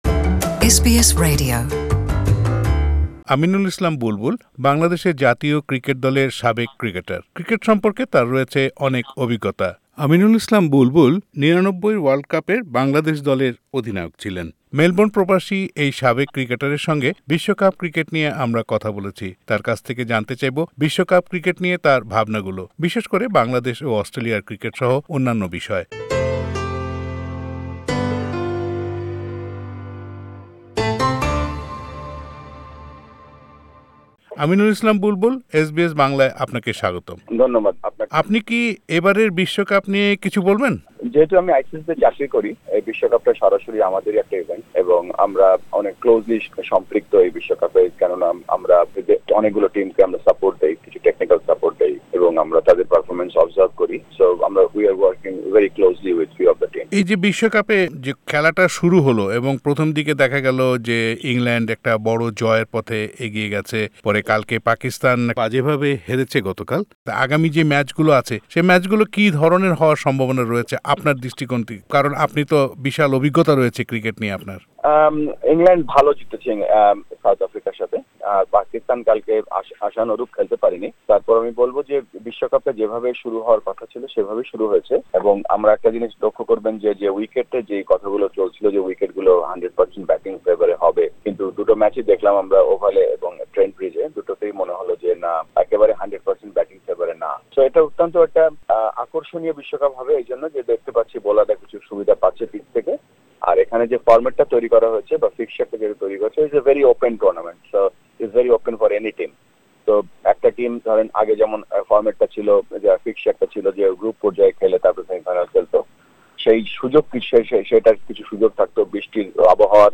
Interview with Former Bangladesh Cricket captain Aminul Islam Bulbul